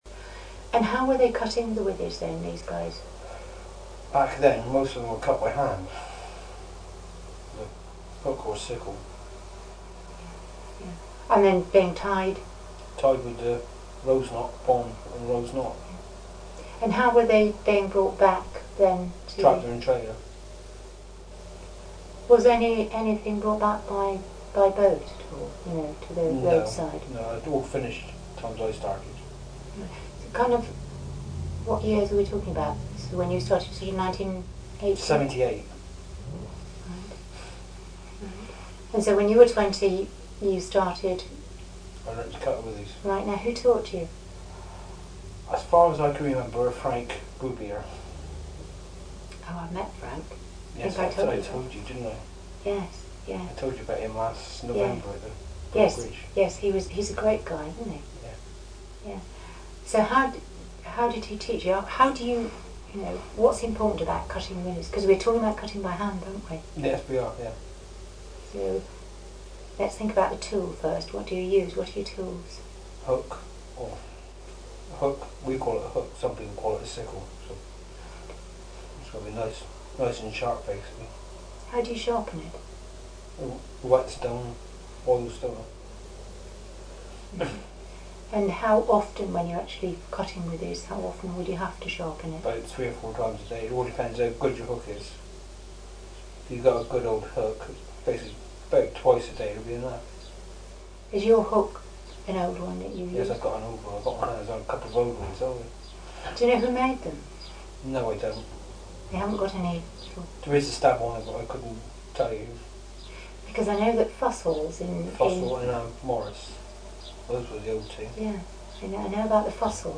For access to full interview please contact the Somerset Heritage Centre.